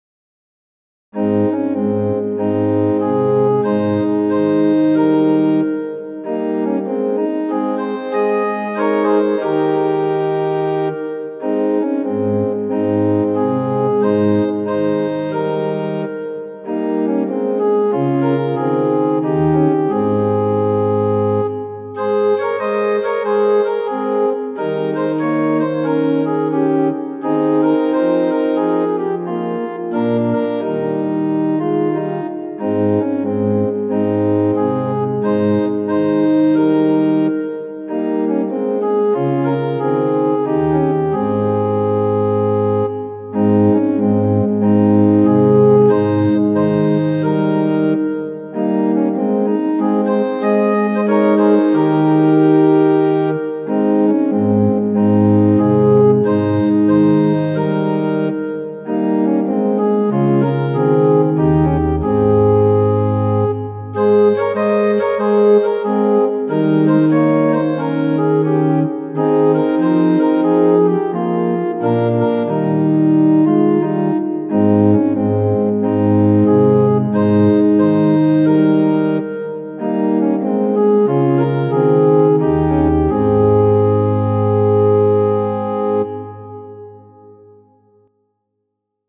S21-307 ダビデの子、ホサナ ♪賛美用オルガン伴奏音源： ・2回演奏しています もし自動的に音声が流れない場合、ここをクリック⇒ 詞：フィンランドの讃美歌集, 1871 曲：Georg J. Vogler, 1749-1814 Tonality = As Pitch = 440 Temperament = Equal ヨハｌ２：１３ 詩１１８：２５ 1 ダビデの子、ホサナ。